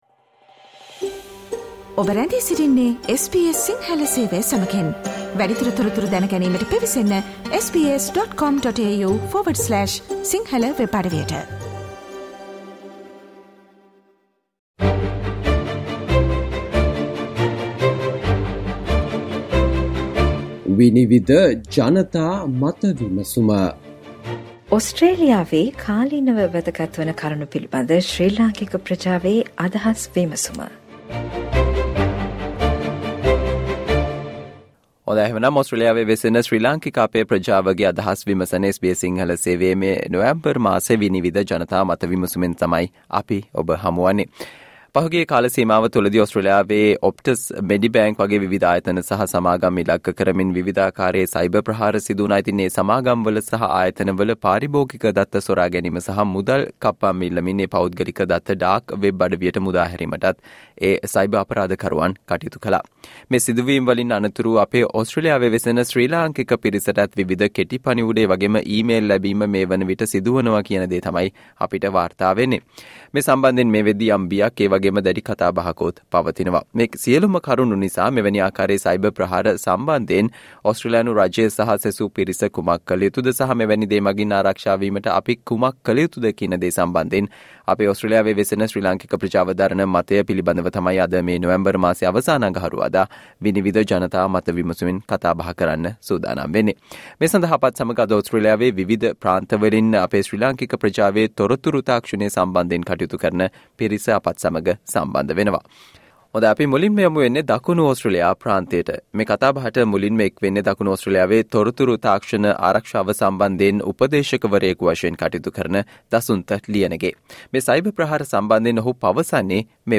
Panel discussion consists with the following invitees